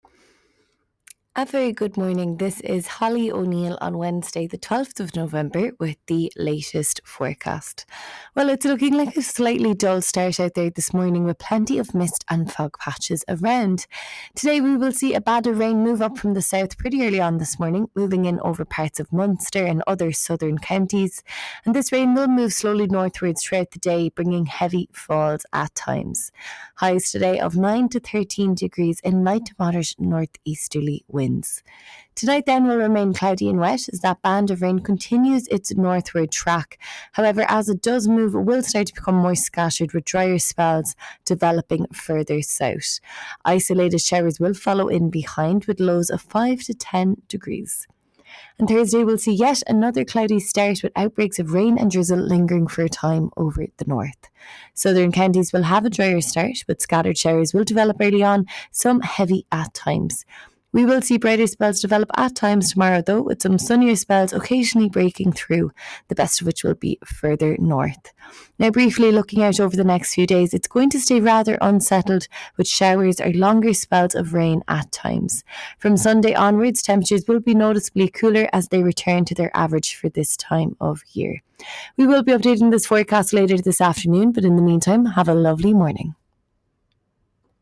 Weather Forecast from Met Éireann